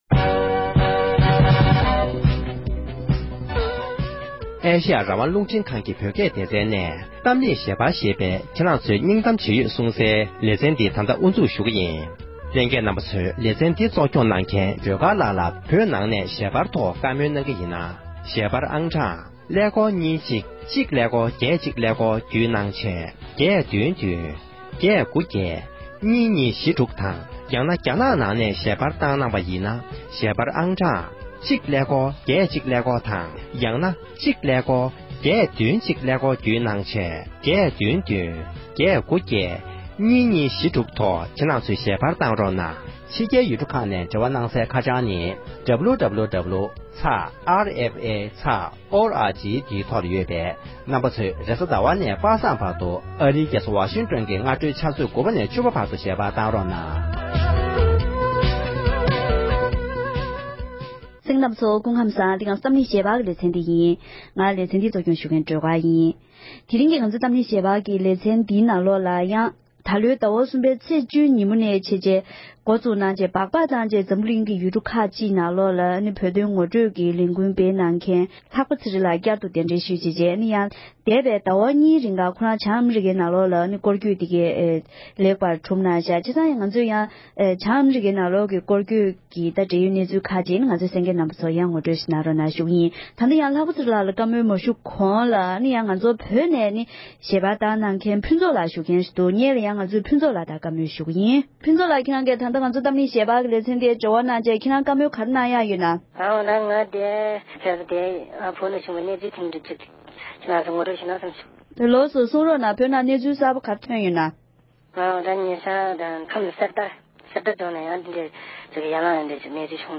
༄༅༎དེ་རིང་གི་གཏམ་གླེང་ཞལ་པར་གྱི་ལེ་ཚན་ནང་དུ་བོད་ནས་ཞལ་པར་གནང་མཁན་བོད་མི་གཉིས་ནས་བོད་ནང་གི་གནས་སྟངས་ངོ་སྤྲོད་གནང་བ་དང༌།